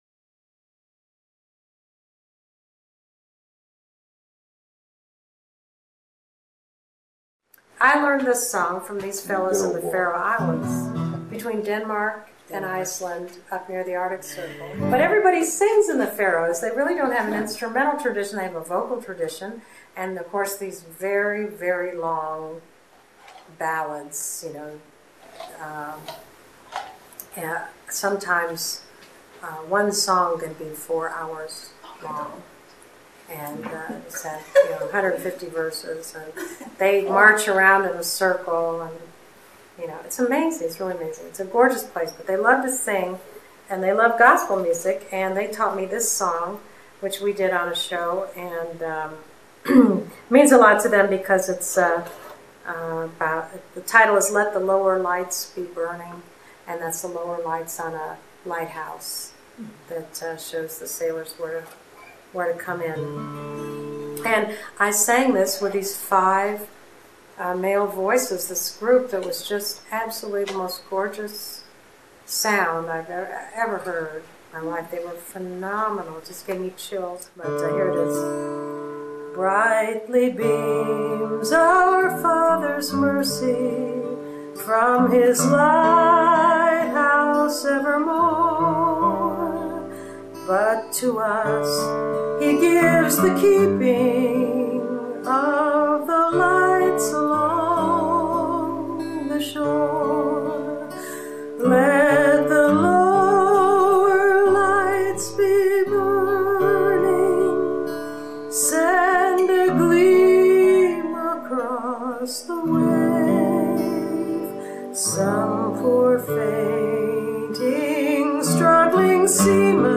"Kitchen concert" Santa Fe, NM July 26
Hymn learned in the Faroe Islands